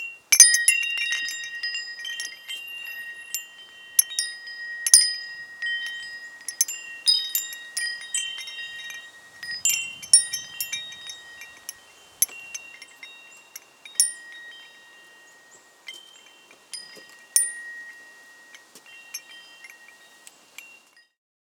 windchime1.L.wav